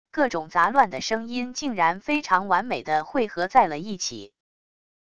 各种杂乱的声音竟然非常完美的汇合在了一起wav音频